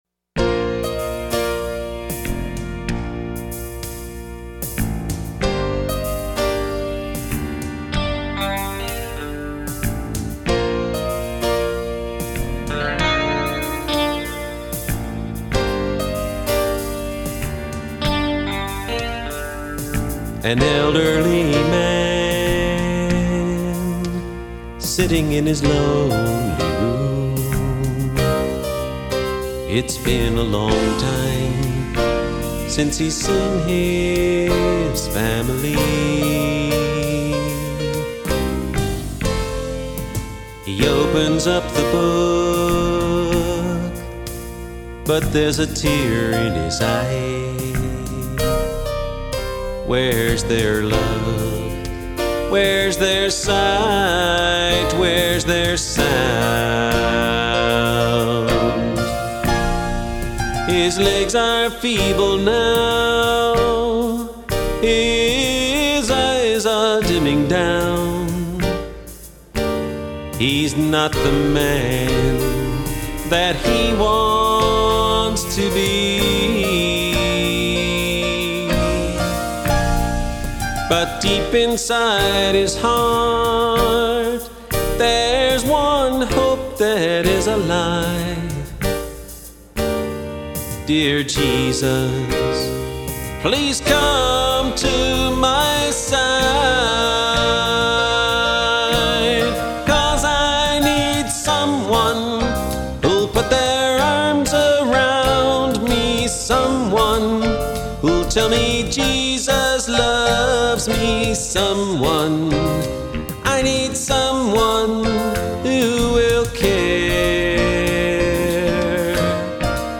This was the first album that we recorded in our own studio.